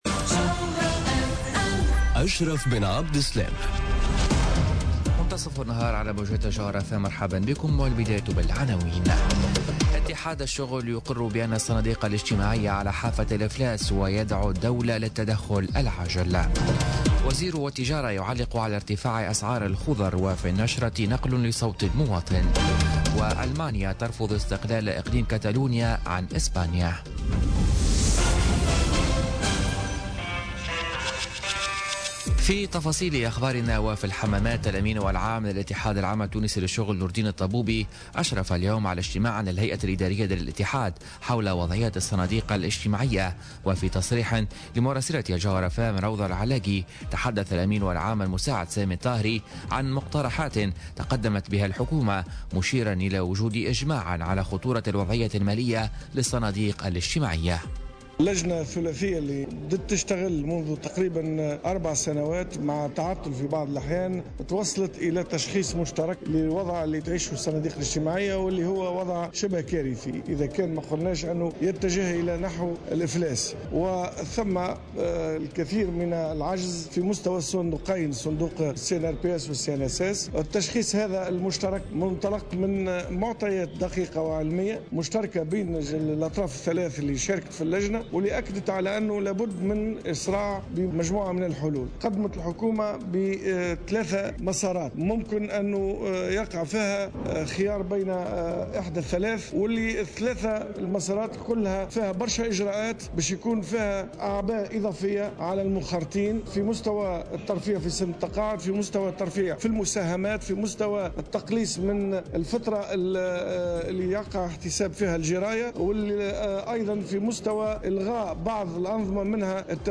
نشرة أخبار منتصف النهار ليوم الإربعاء 11 أكتوبر 2017